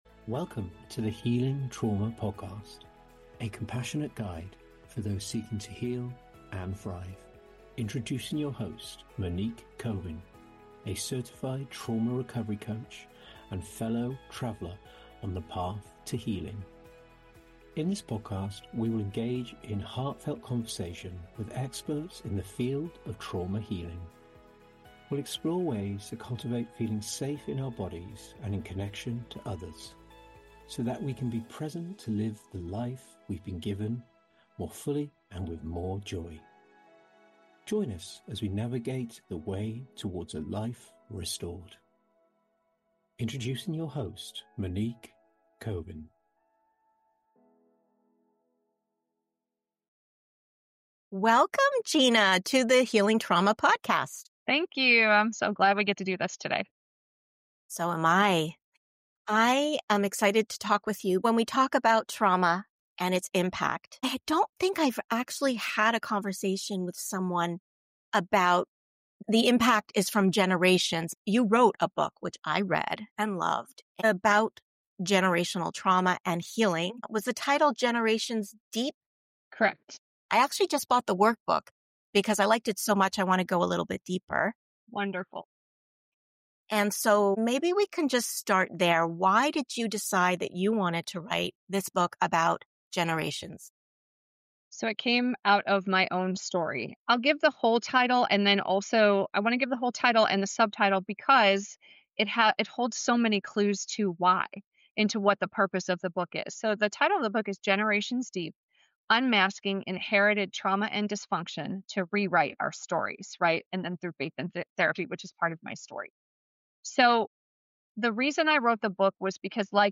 This podcast is here to light your path on the healing journey, offering empathy and hope along the way. Through conversations with trauma experts, w'ell share insights and practical support to help you heal and move forward.